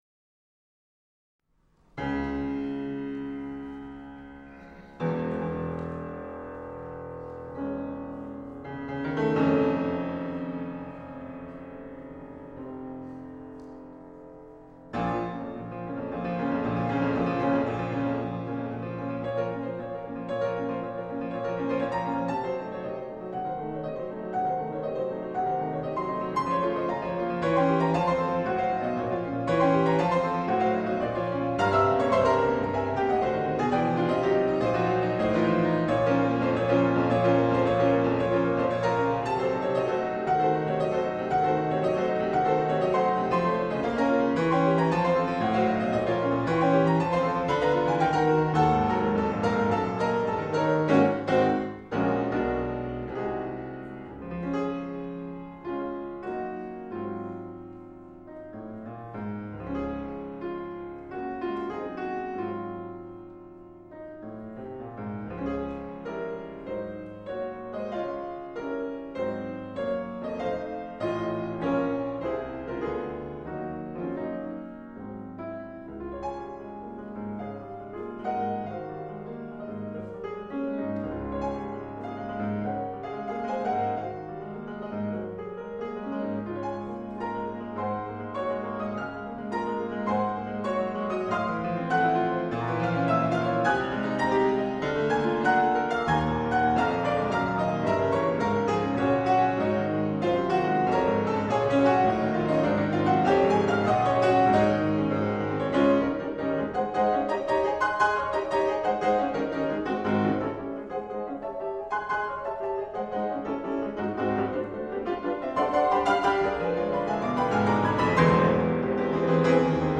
Concert Recording Excerpts for Your Enjoyment
Grave — Doppio movimento